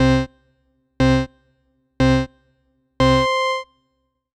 Pacer Beeps Intense.wav